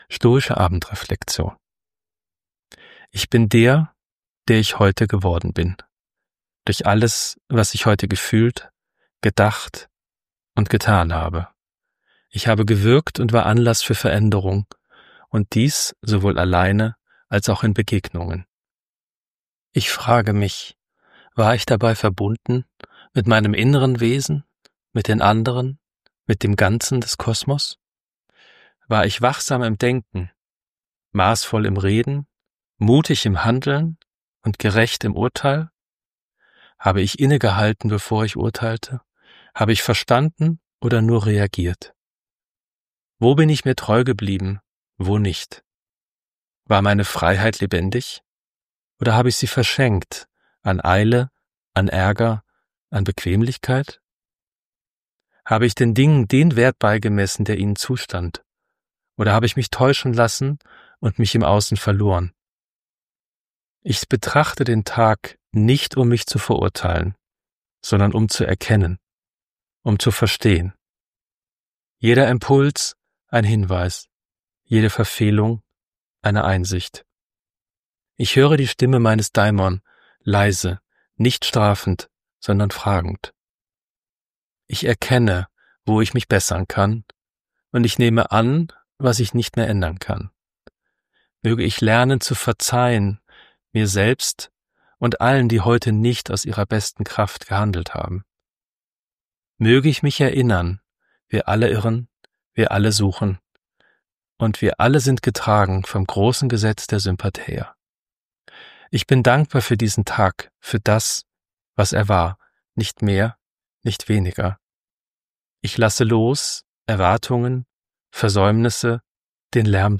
Eine Audioversion der Reflexion ohne An- und Abmoderation findet sich